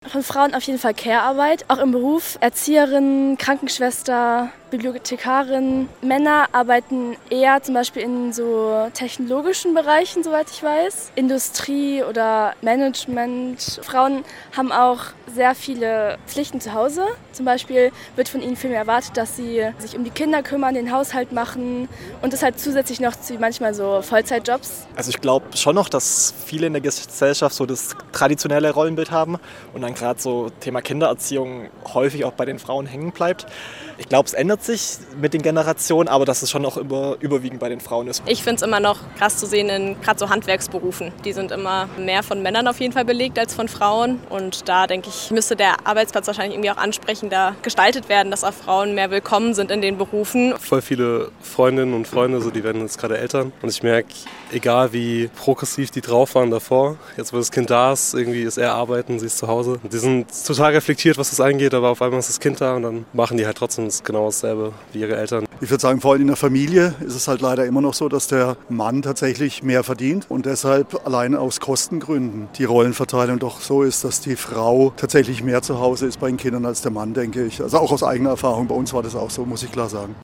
Radio- und Video-Umfrage in der Freiburger Innenstadt im Rahmen des BOGY-Praktikums im SWR Studio Freiburg.